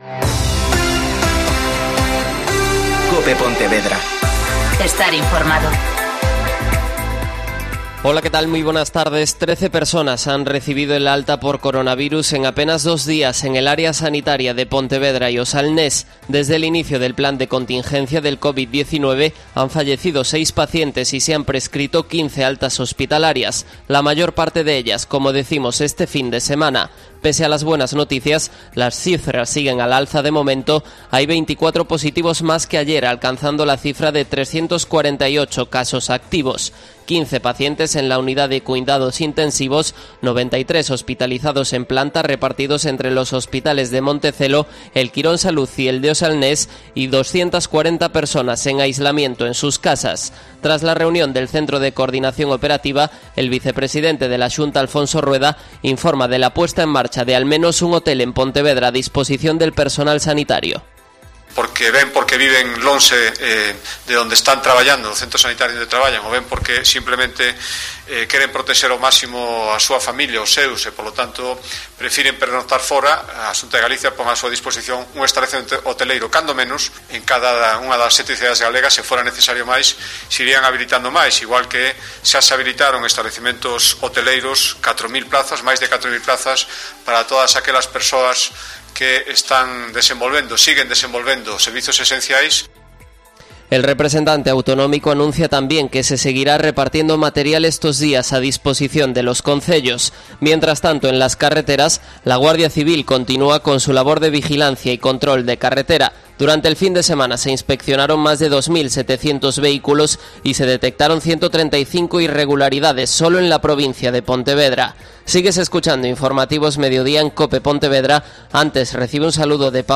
Mediodía COPE Pontevedra (Informativo 14:20h)